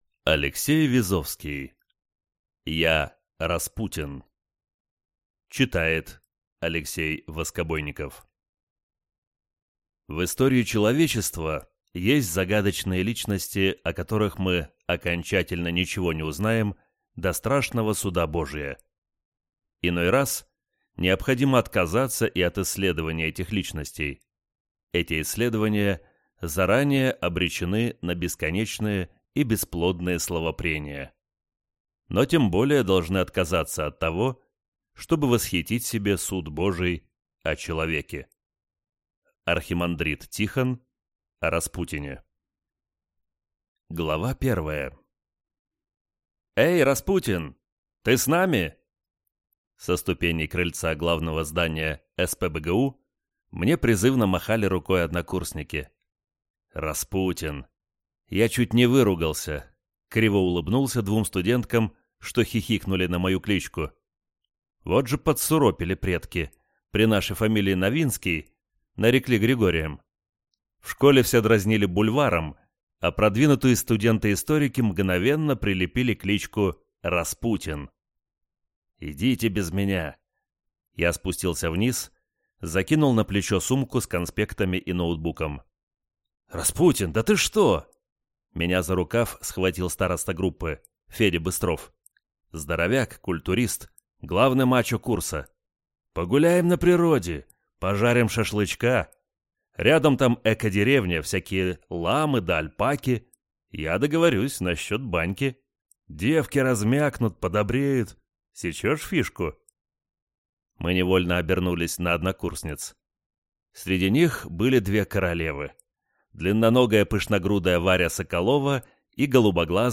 Аудиокнига Я – Распутин | Библиотека аудиокниг